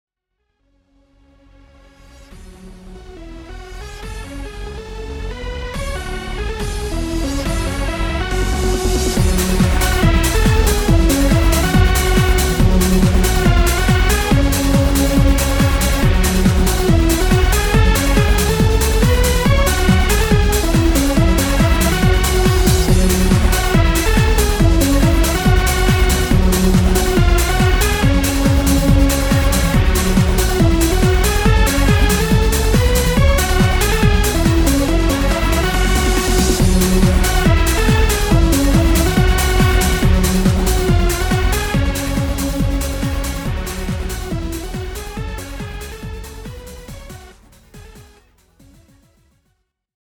そんな呼び掛けに応じてくれた、精鋭達渾身の四つ打ちトラックのみを集めたアルバムです！
楽曲アレンジ